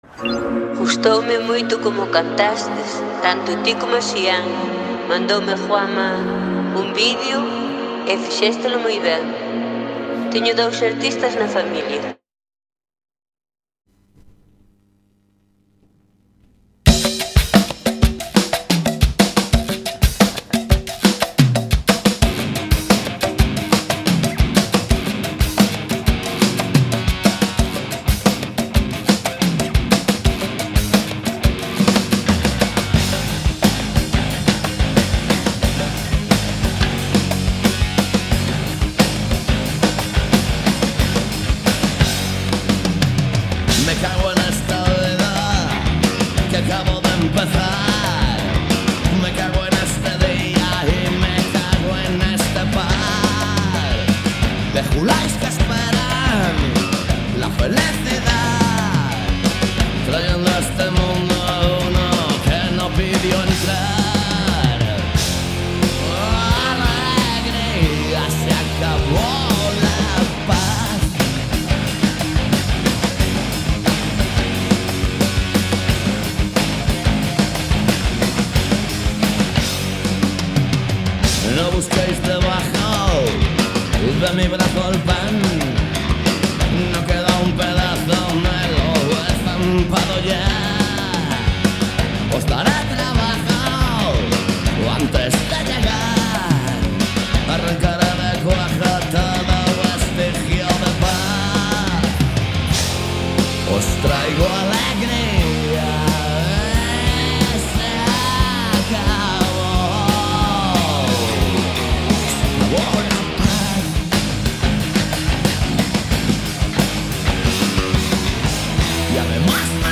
Todo iso misturado con boa música e un pouco de humor se o tema o permite. Cada martes ás 18 horas en directo.
Programa emitido cada mércores de 19:00 a 20:00 horas.